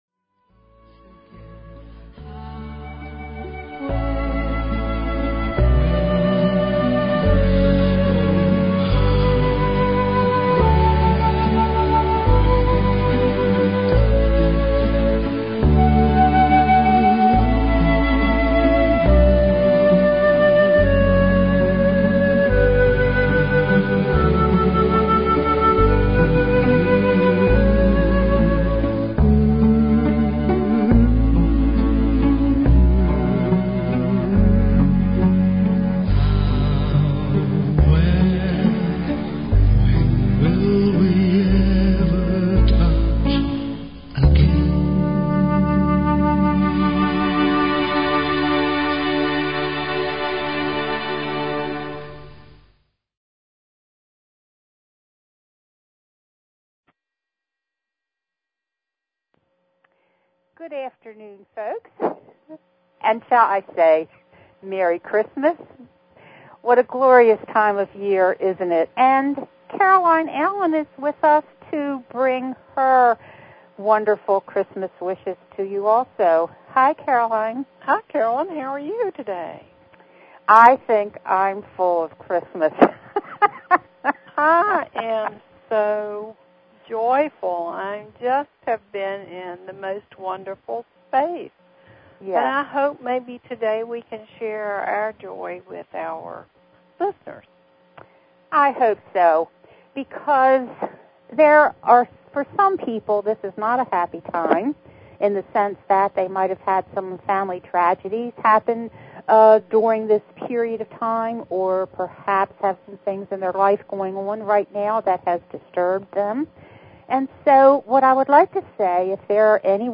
What I attempt to do in this series is to use the messages that I have received from the Spiritual Hierarchy, along with my own personal past life memories, and weave this information with current events, news stories and scientific confirmation. There will be times when I will read from the Earth records to give a deeper understanding of planetary history and current events.